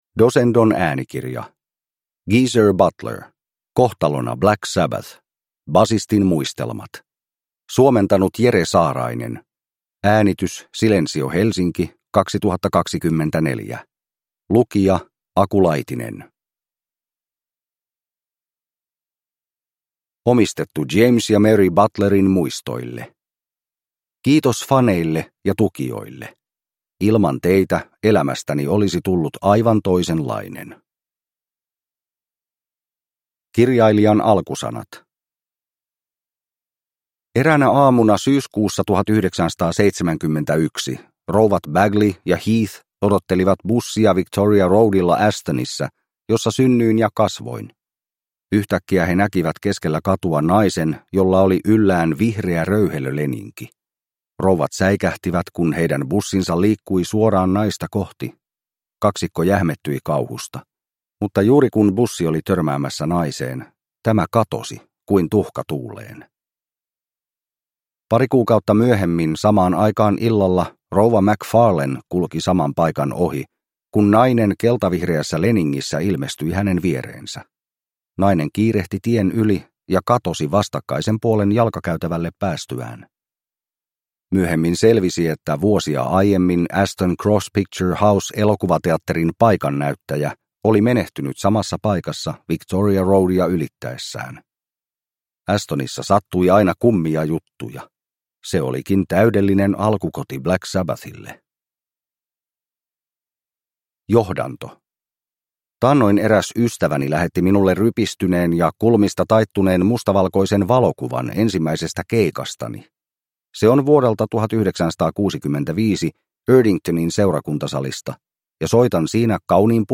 Kohtalona Black Sabbath – Ljudbok